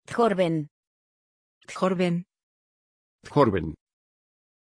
Aussprache von Tjorven
pronunciation-tjorven-es.mp3